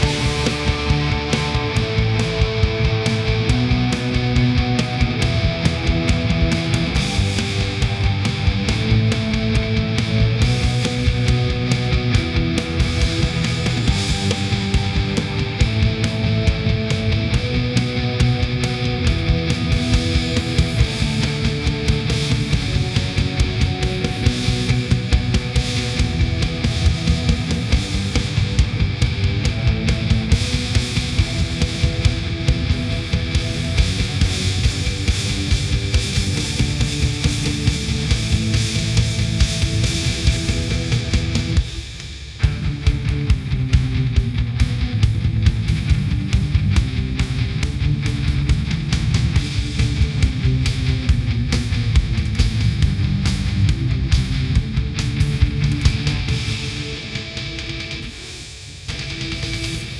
for backsound